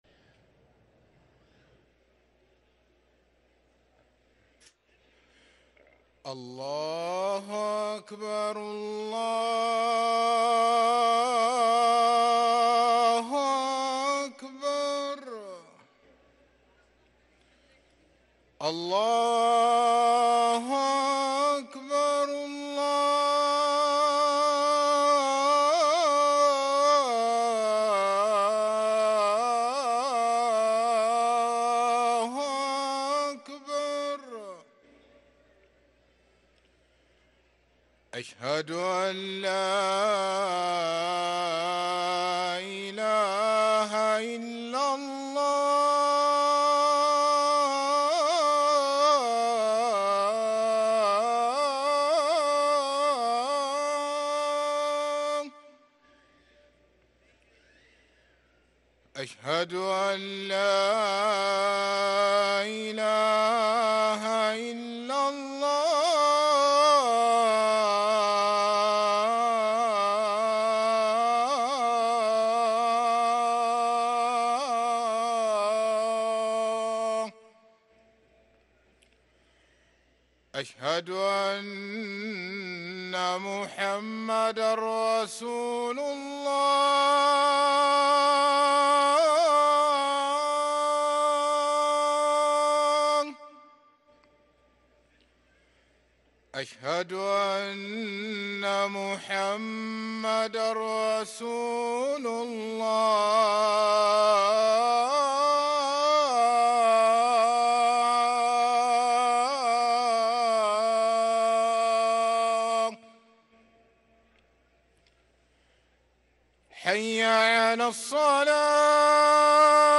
أذان العشاء للمؤذن سعيد فلاته السبت 16 جمادى الأولى 1444هـ > ١٤٤٤ 🕋 > ركن الأذان 🕋 > المزيد - تلاوات الحرمين